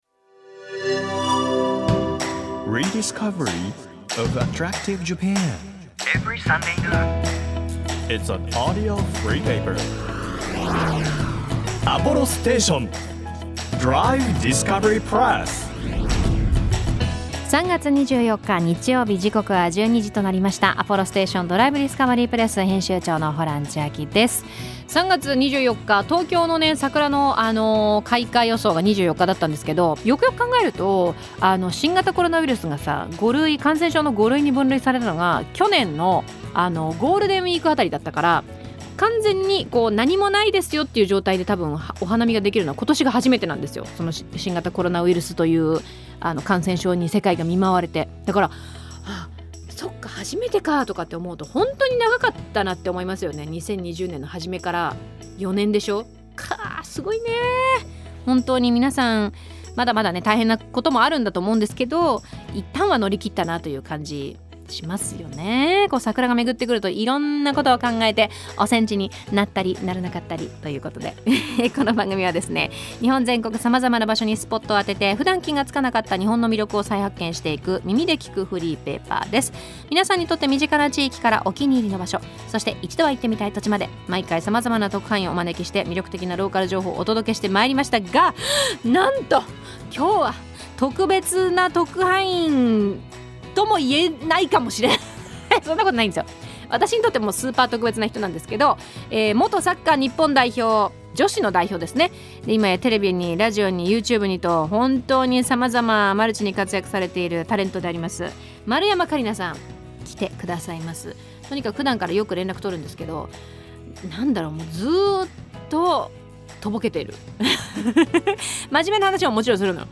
ホラン千秋編集長と一緒に日本全国の魅力を発見していきましょう！